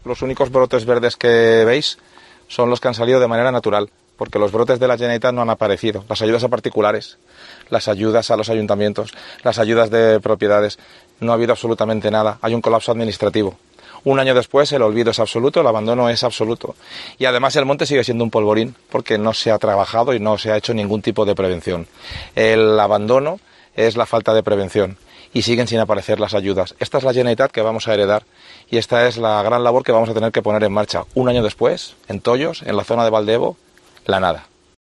El presidente del PPCV ha hecho estas declaraciones en Tollos, junto al alcalde de la localidad, Félix Frau, tras visitar la zona que el fuego arrasó el pasado verano en Vall d’Ebo, acompañado de alcaldes de la zona y de los integrantes del PP al Congreso por la provincia de Alicante, Macarena Montesinos y César Sánchez.